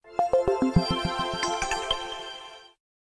알림음 8_melody4.ogg